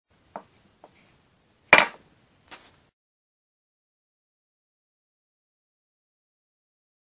Bottle on Table
Category: Sound FX   Right: Personal
Tags: radio drama